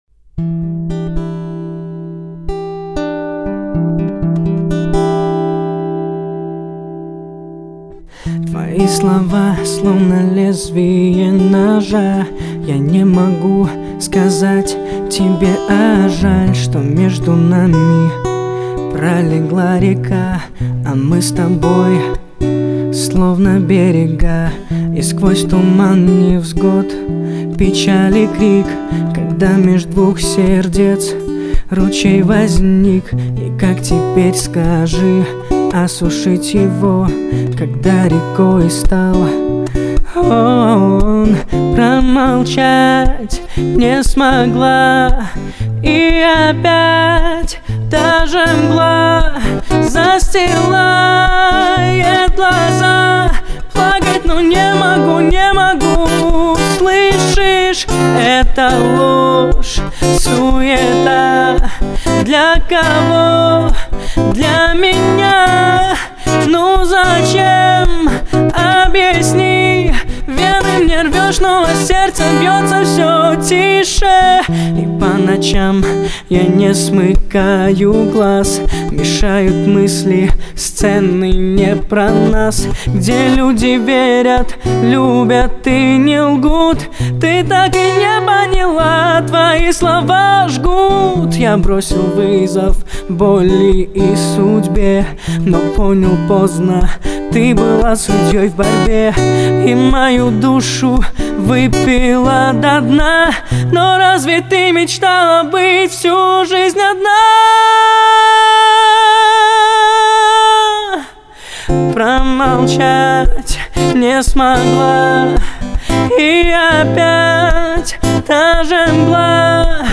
студийная запись!! music